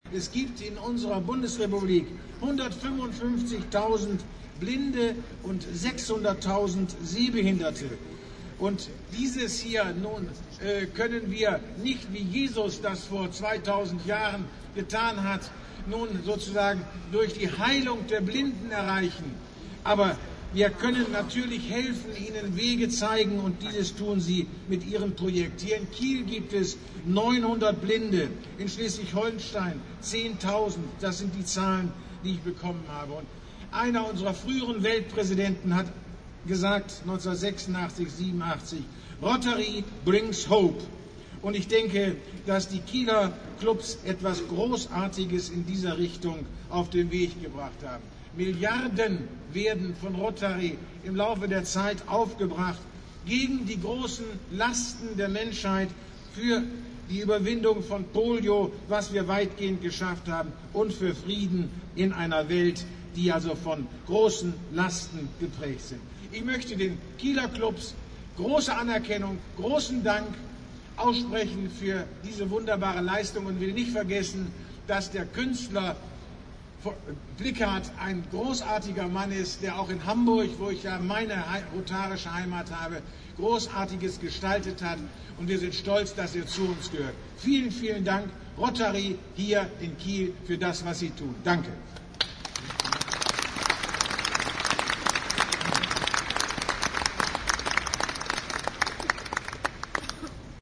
Die abhörbaren Ausschnitte sind in kopfbezogener Stereophonie aufgenommen worden. Es empfihelt sich daher, ihnen unter Kopfhörern zu lauschen.